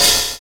Closed Hats
Wu-RZA-Hat 41.wav